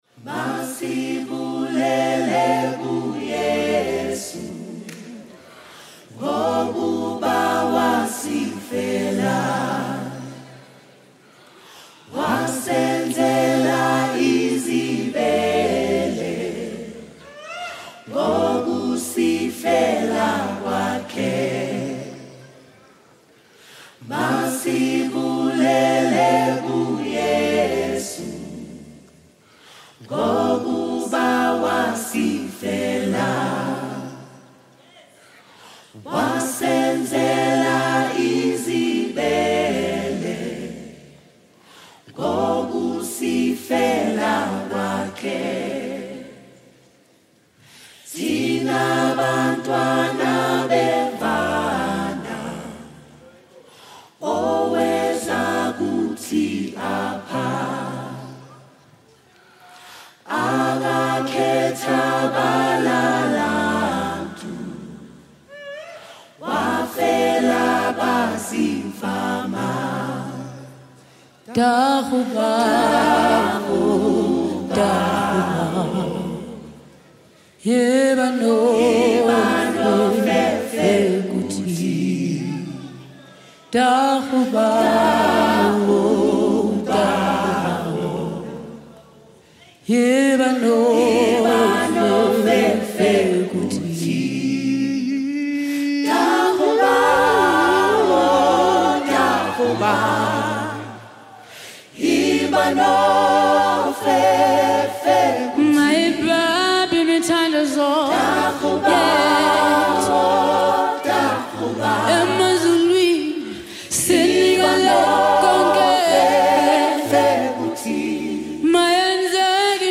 Award winning singer-songsmith